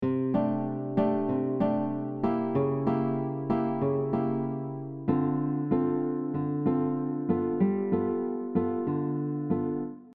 This mellow and beautiful style uses syncopated rhythms with a fingerpicking style of playing.
This last example shows how you can mix up all the different rhythms while still switching between chords.
Bossa nova guitar pattern with mixed rhythms
Bossa-Nova-pattern-7.mp3